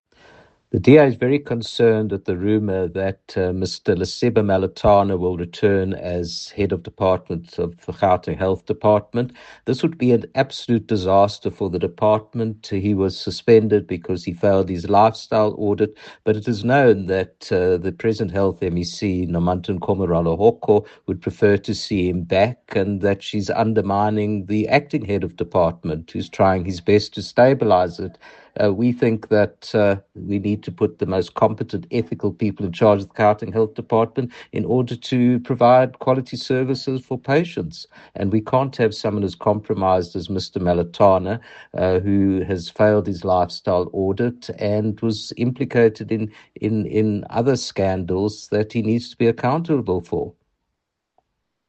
soundbite by Jack Bloom MPL.